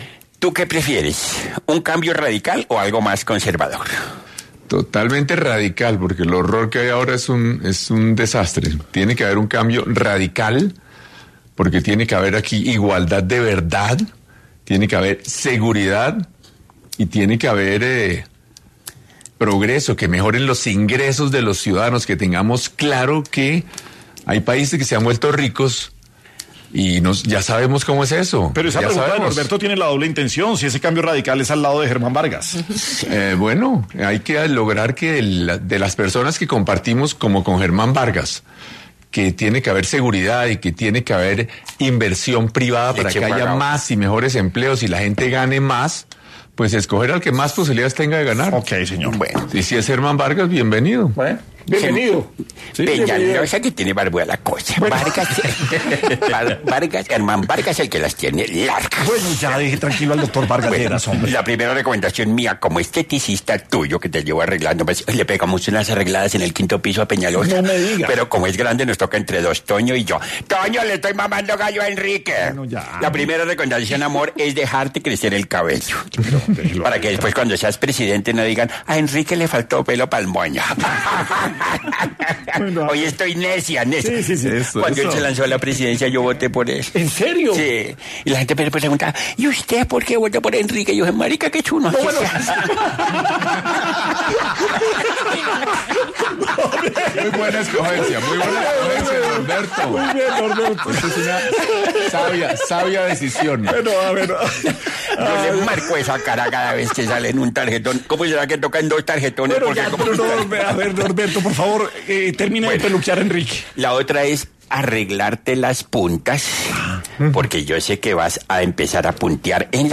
El precandidato presidencial y exalcalde de Bogotá, Enrique Peñalosa estuvo en Sin Anestesia de La Luciérnaga para hablar sobre sus posibles alianzas